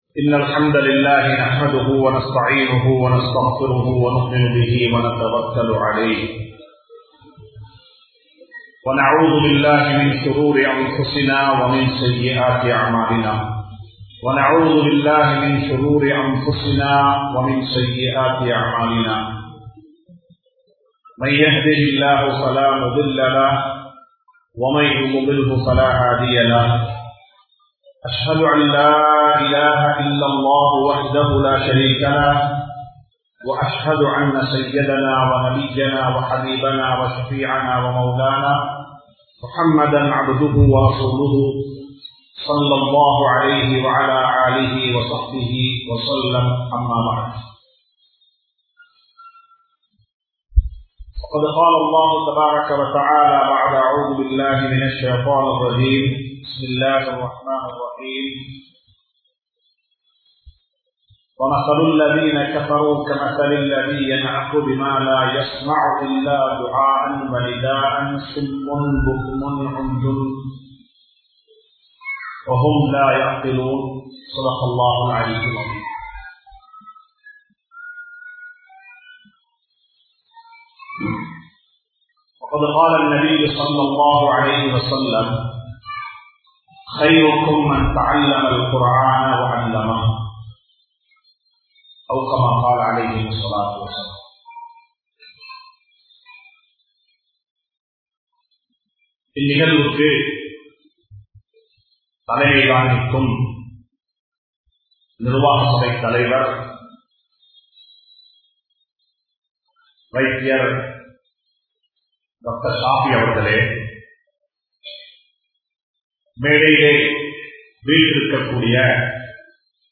Markak kalviyin Perumathi! (மார்க்க கல்வியின் பெறுமதி!) | Audio Bayans | All Ceylon Muslim Youth Community | Addalaichenai
Theliyagonna Jumua Masjidh